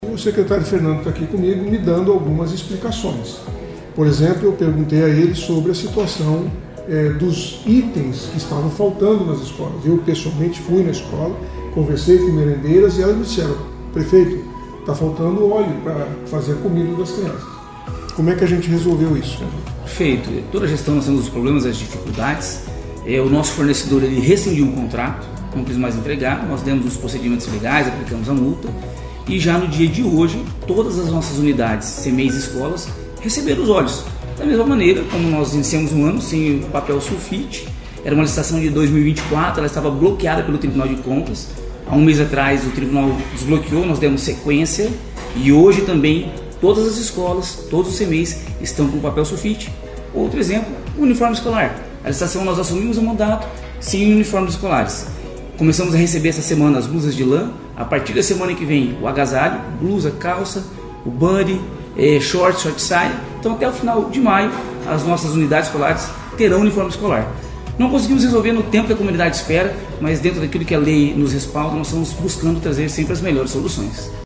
Na redes sociais, o prefeito Silvio Barros e o secretário de Educação de Maringá Fernando Brambilla falaram sobre a falta de óleo para a merenda nos Cmeis e escolas da rede municipal de ensino.